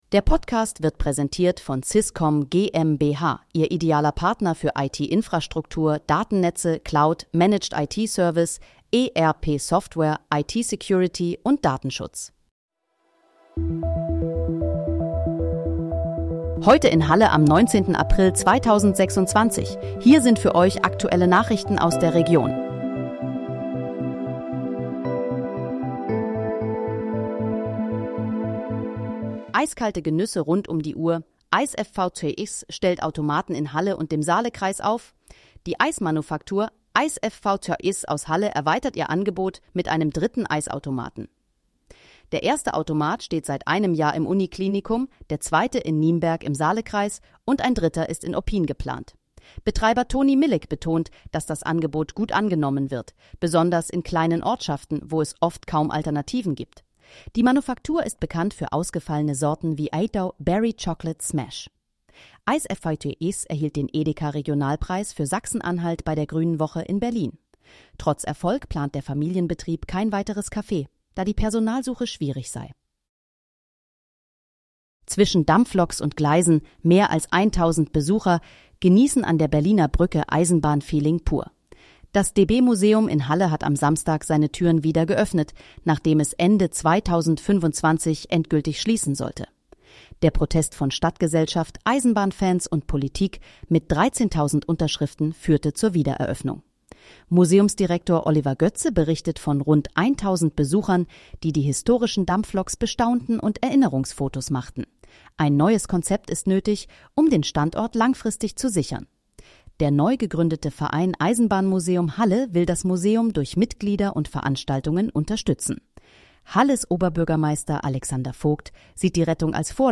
Heute in, Halle: Aktuelle Nachrichten vom 19.04.2026, erstellt mit KI-Unterstützung